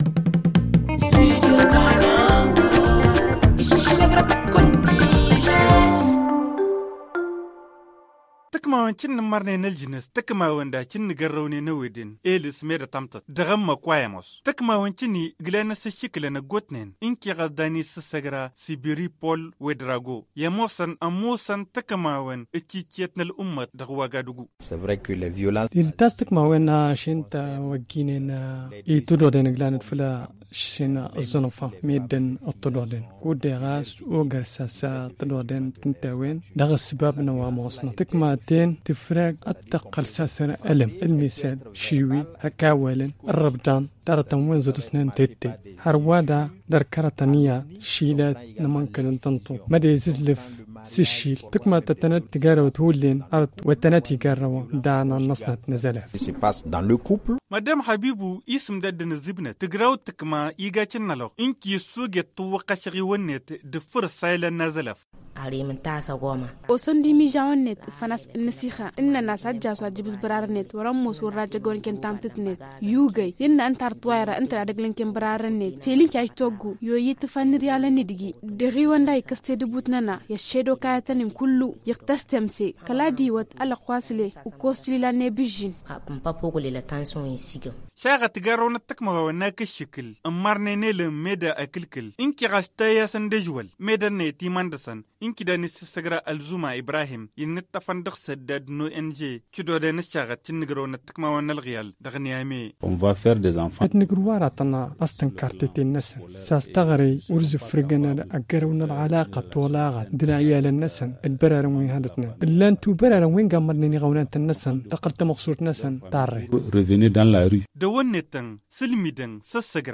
Le magazine en tamasheq